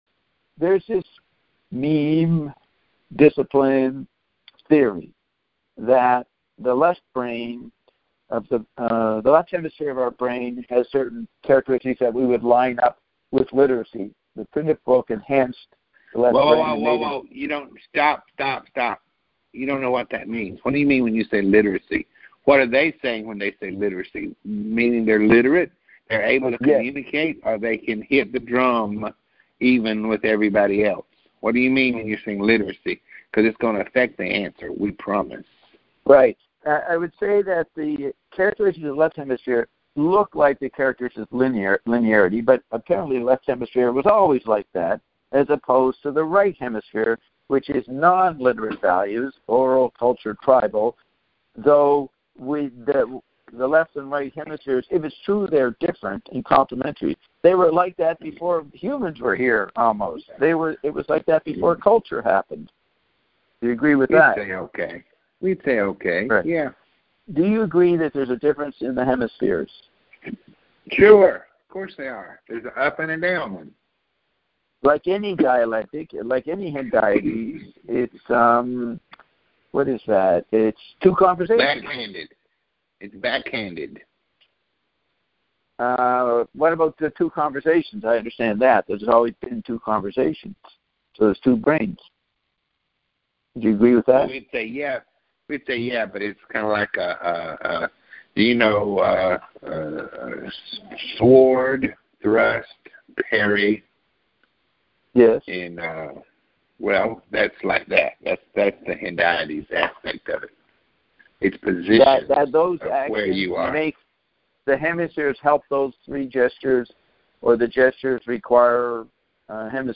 If the audio were better quality, we could be heard by hackers.”